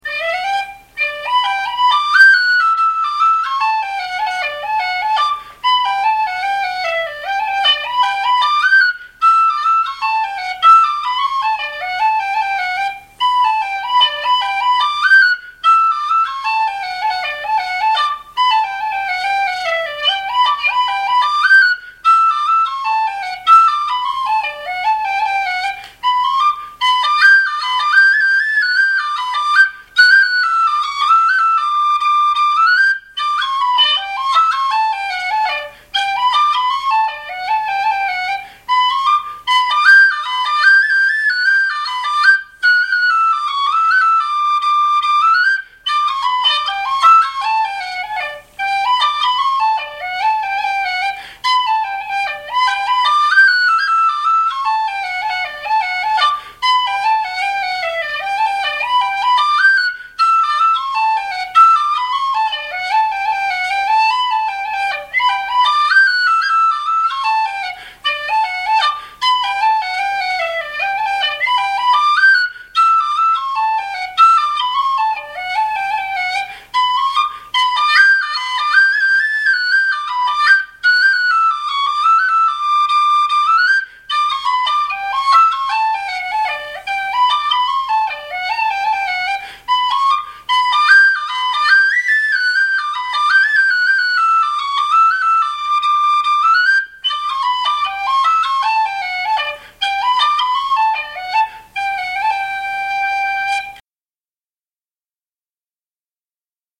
Dunphy's hornpipe G pdf 30KB txt